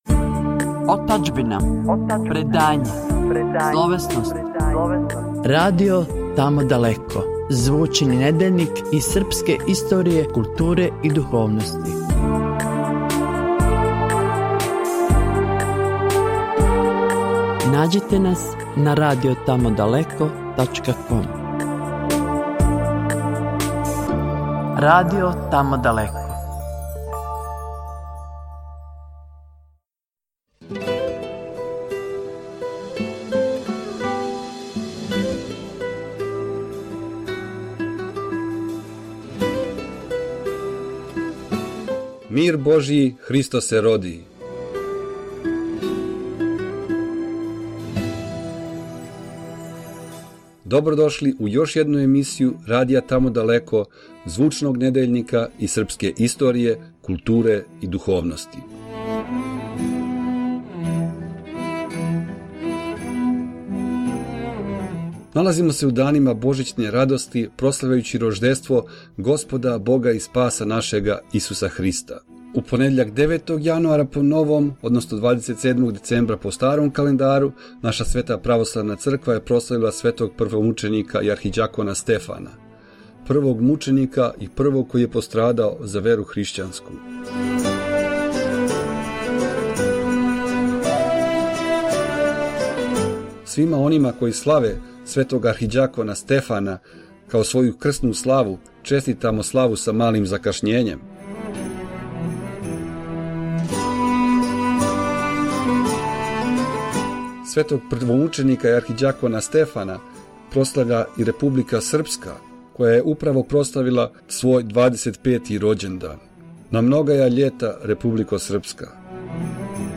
Радио емисија „Тамо далеко“ – звучни недељник српске историје, културе и духовности
Интернет радио емисија „Тамо далеко“ је од локалног (у овом случају новозеландског нивоа) прерасла у својеврсну звучну књигу подсећања за све Србе, како оне у дијаспори тако и оне у матици.